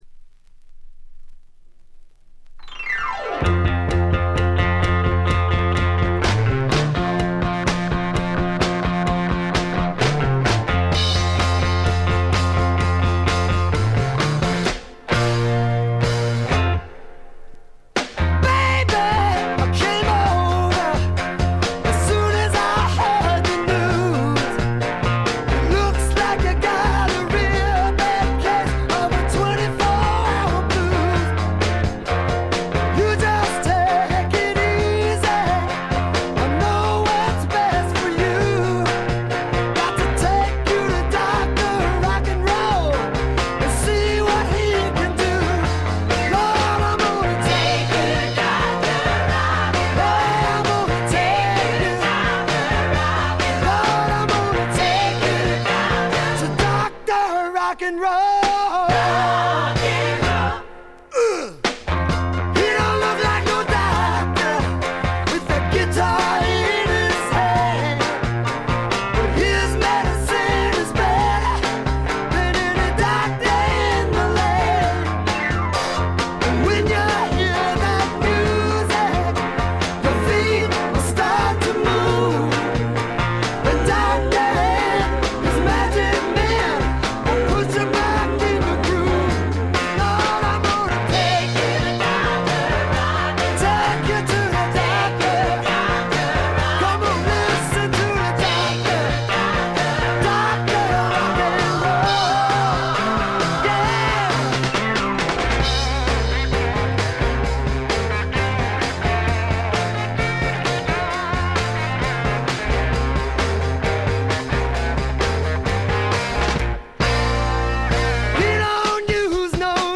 ほとんどノイズ感無し。
ジャケットは70年代末のAOR的なセンスですが、中身は究極のスワンプアルバムです。
スワンプロック究極の逸品かと思いますよ。
試聴曲は現品からの取り込み音源です。